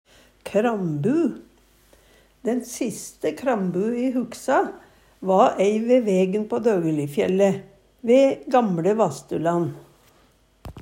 krambu - Numedalsmål (en-US)